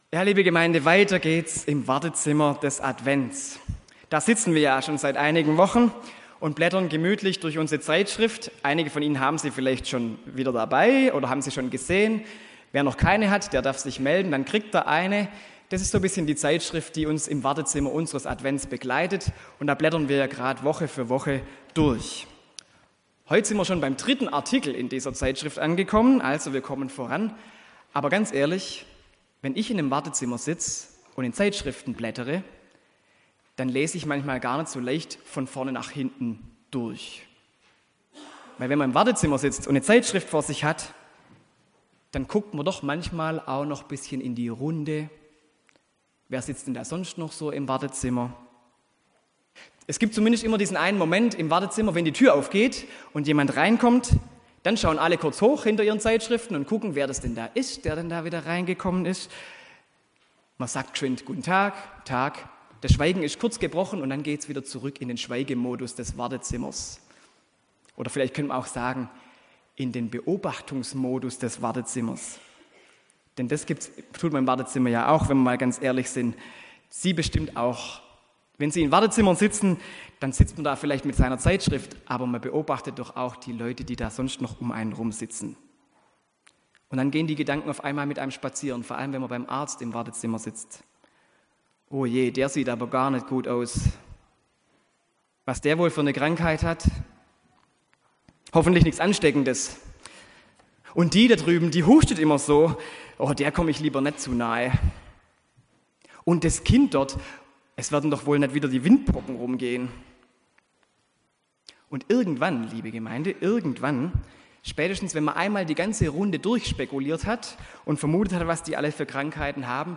Gottesdienst am 3. Advent Die Predigt zum Nachlesen: 1.